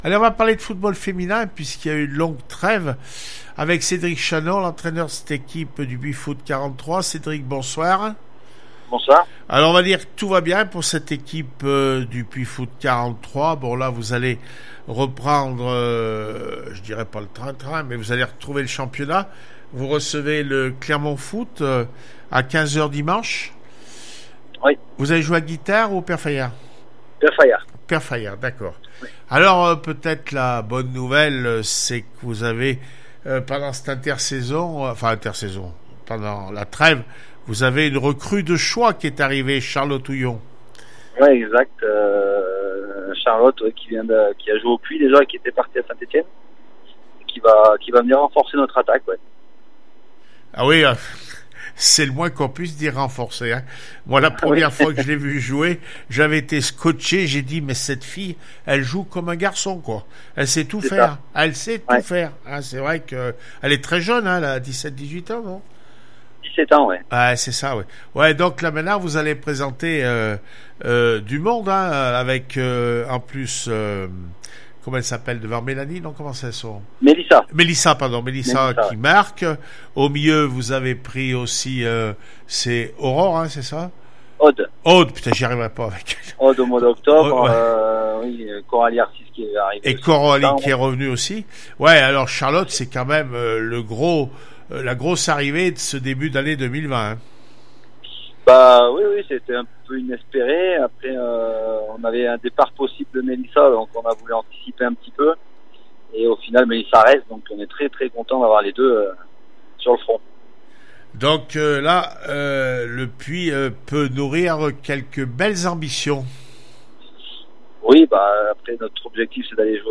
1 février 2020   1 - Sport, 1 - Vos interviews, 2 - Infos en Bref   No comments